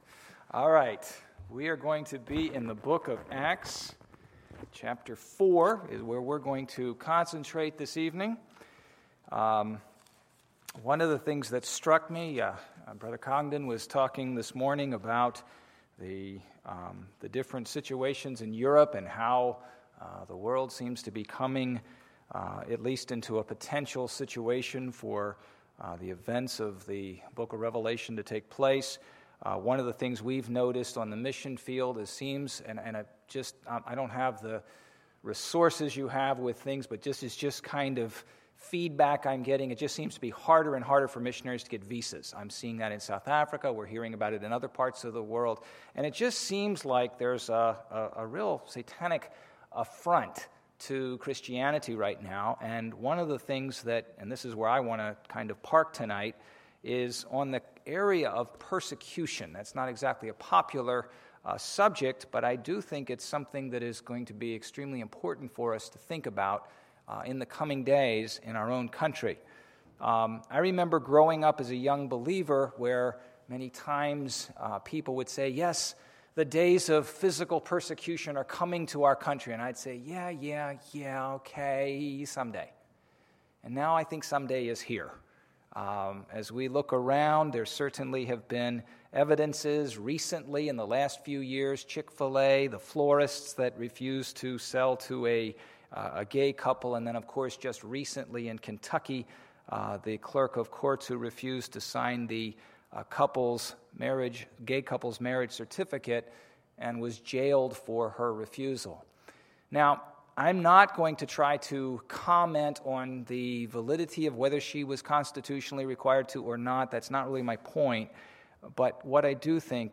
Tuesday, September 29, 2015 – Missions Conference Tuesday Evening Service